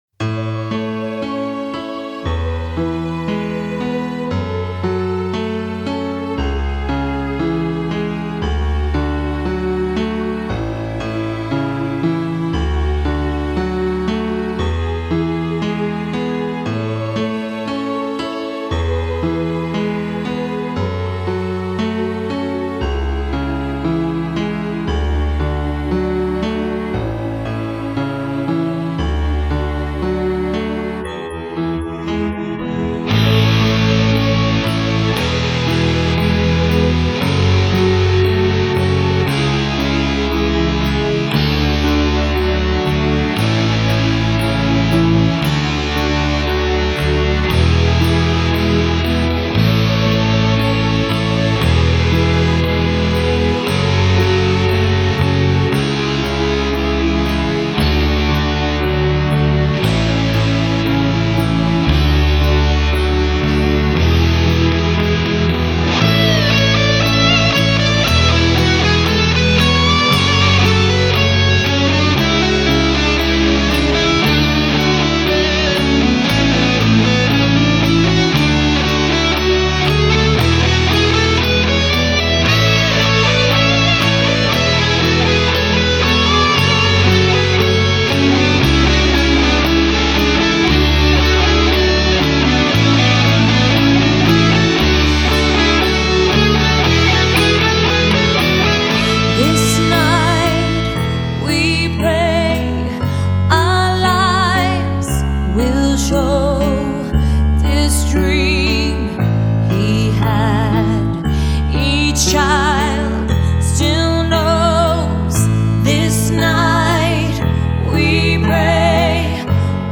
4/4 60以下
欧美歌曲